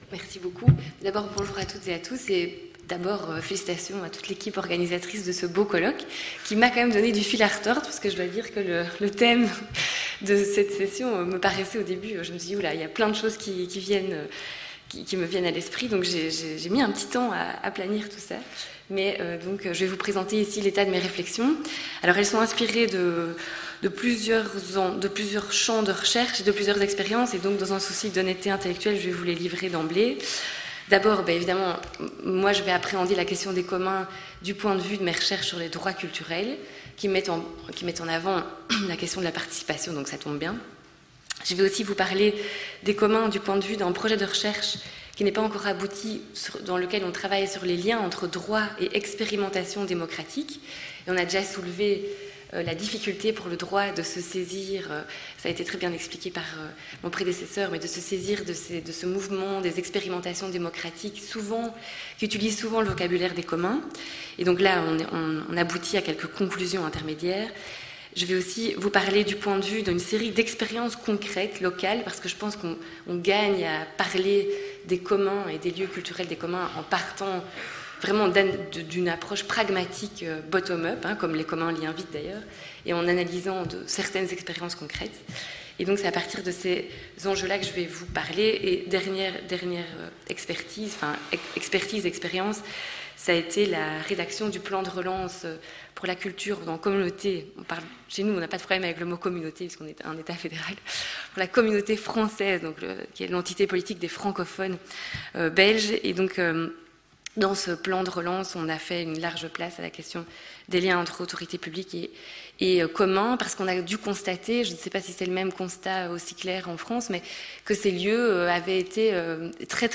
Pour la quatrième année consécutive, les élèves conservateurs de l’Institut national du patrimoine (INP) organisent conjointement avec les doctorants de l'Institut des sciences sociales du politique (ISP – UMR 7220) une journée d'étude portant sur des problématiques communes au droit et au patrimoine. Elle se déroulera le 30 mars 2022 et investira, en quatre séquences, les nouveaux enjeux dans le champ de la culture et du patrimoine, au prisme des communs.